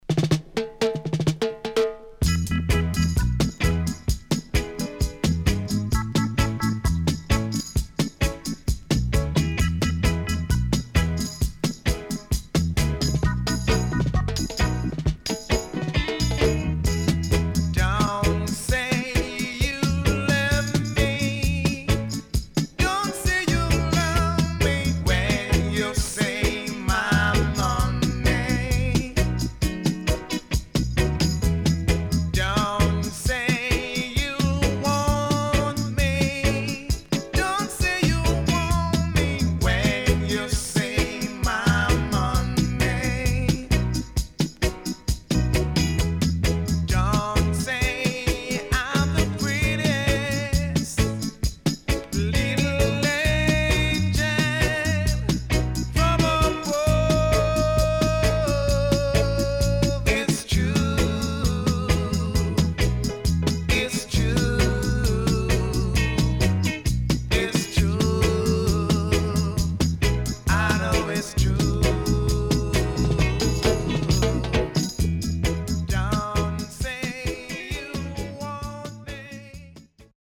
Nice Self Remake
SIDE A:少しチリノイズ、プチパチノイズ入ります。